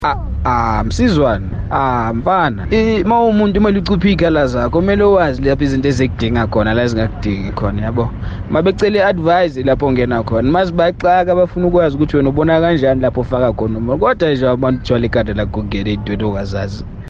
Kaya Drive listeners shared why intervening in your in-laws problems is a bad idea: